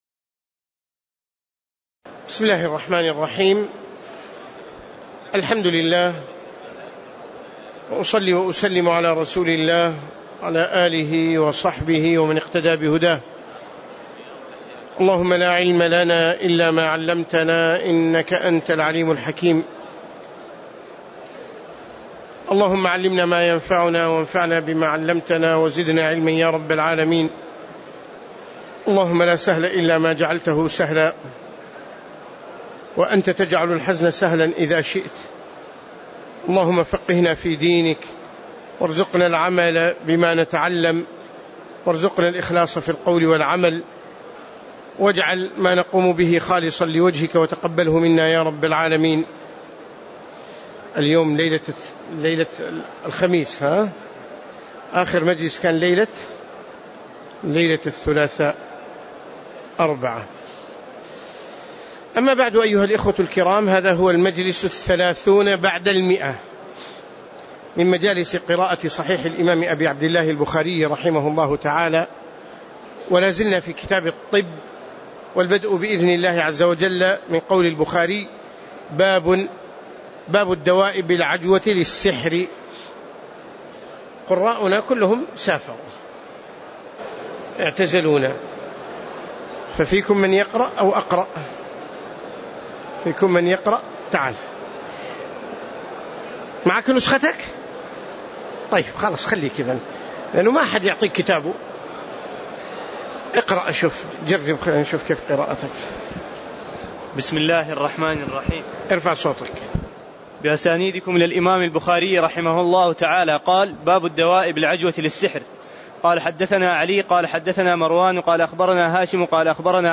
تاريخ النشر ٥ رمضان ١٤٣٨ هـ المكان: المسجد النبوي الشيخ